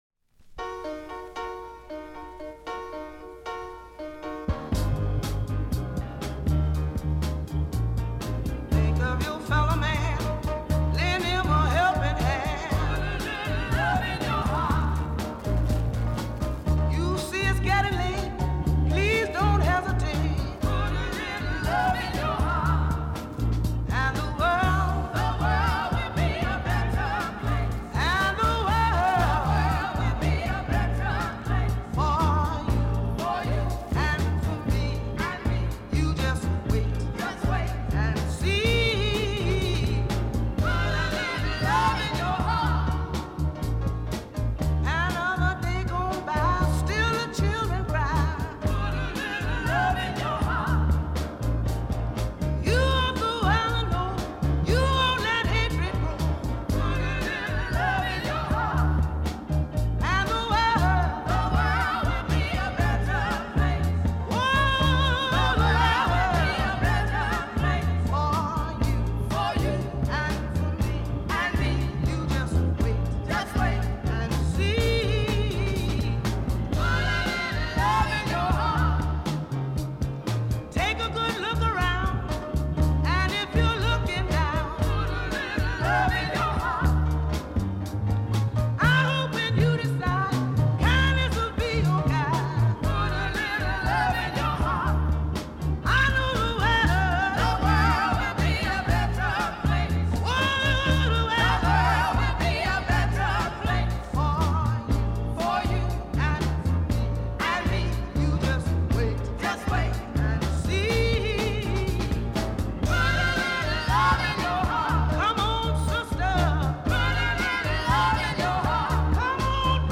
gospel-ized spin